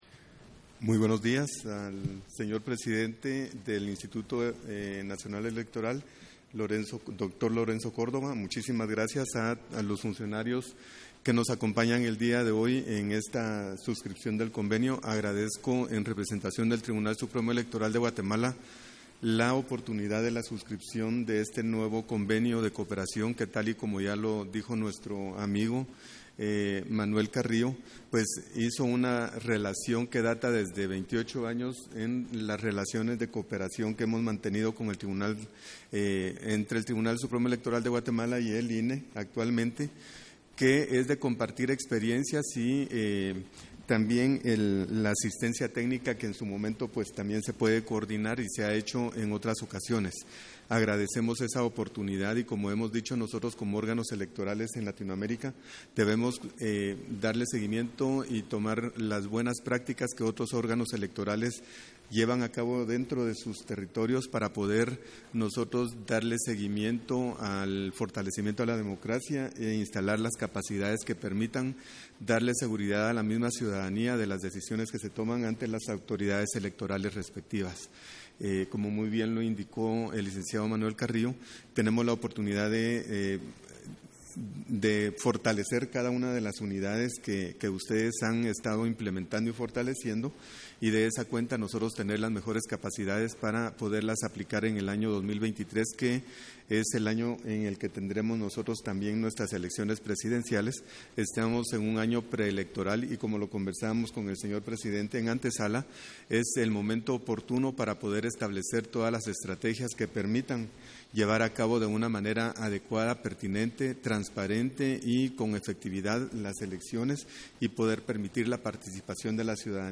Intervención de Ranulfo Rafael Rojas, durante la firma de convenio de colaboración bilateral, INE-Tribunal Supremo Electoral de Guatemala